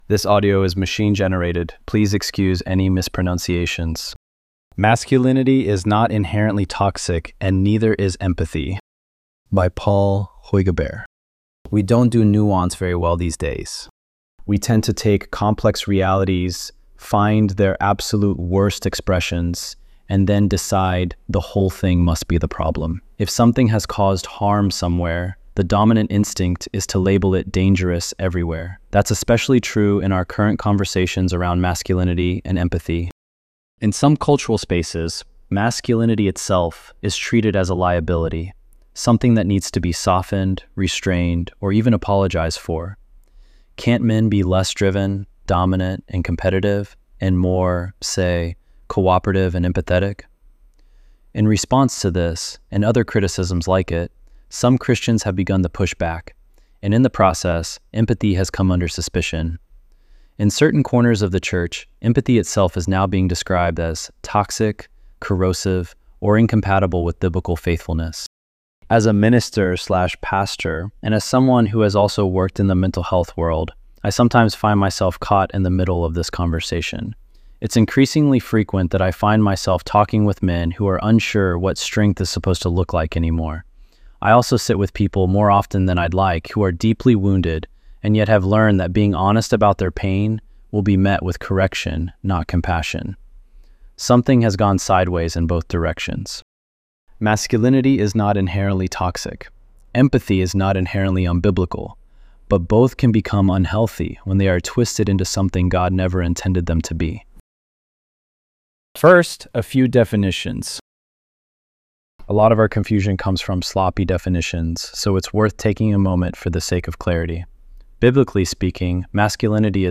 ElevenLabs_3_3.mp3